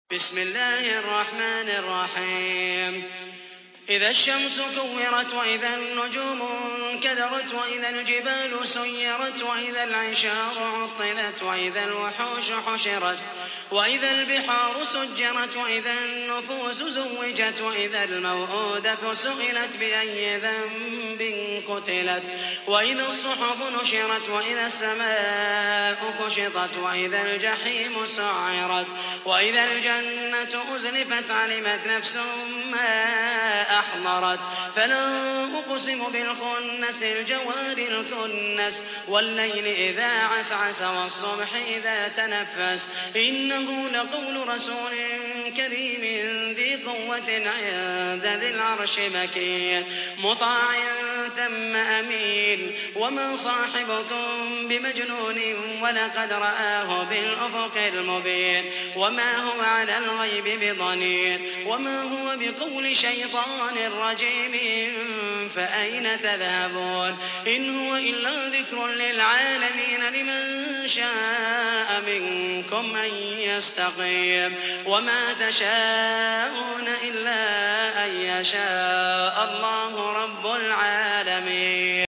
81. سورة التكوير / القارئ